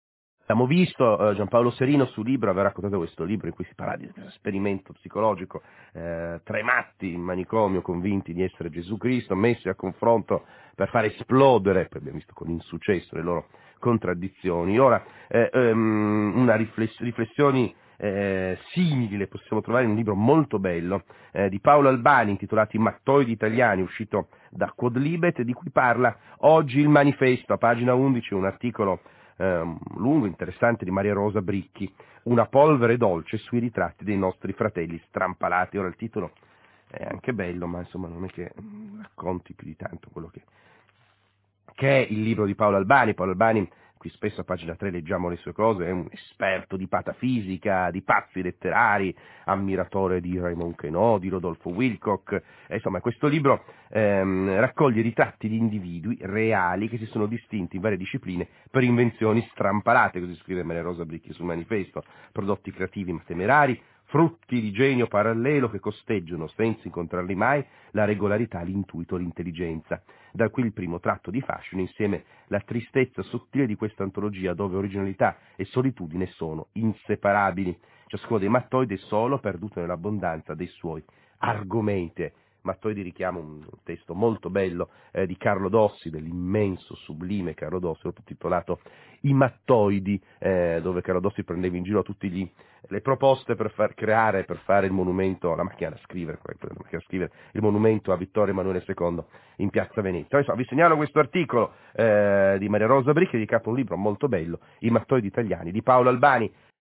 ha commentato questa recensione durante la trasmissione Pagina3 di RaiRadio3, programma radiofonico di approfondimento delle pagine culturali e dello spettacolo, su RadioRAI 3.